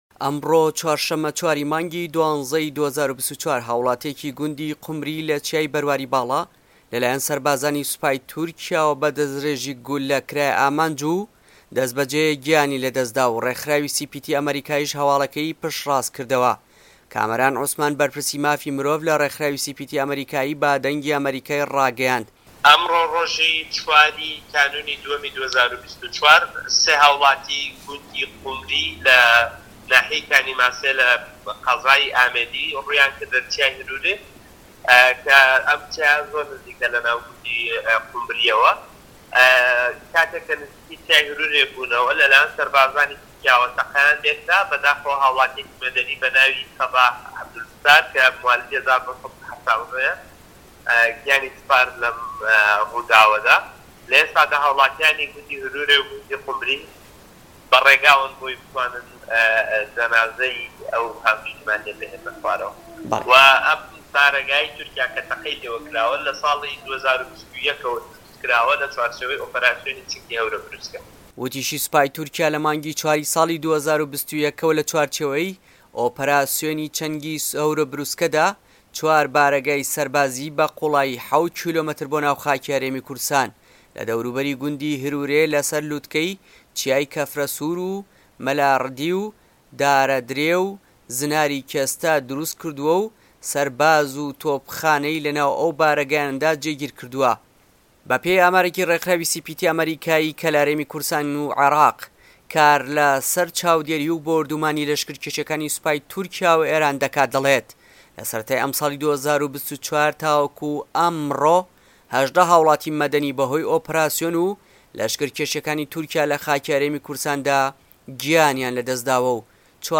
دەقی ڕاپۆرتی